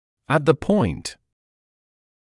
[ət ðə pɔɪnt][эт зэ пойнт]в точке